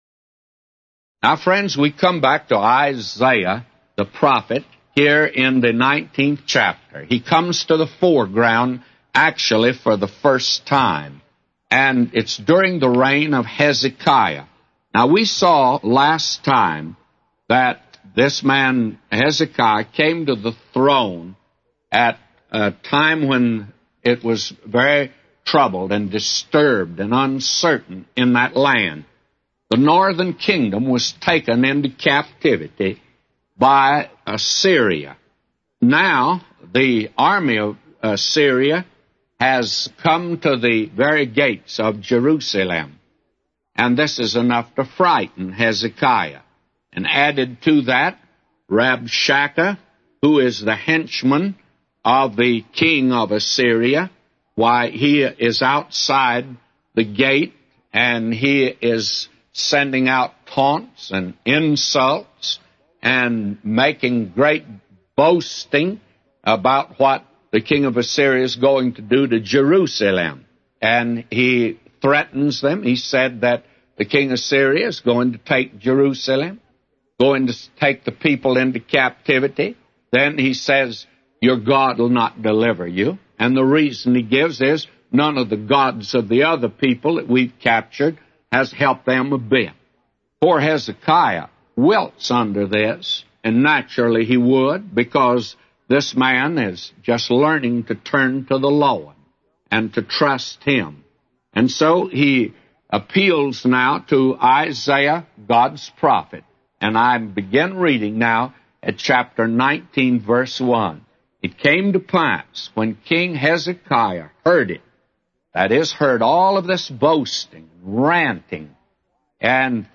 A Commentary By J Vernon MCgee For 2 Kings 19:1-999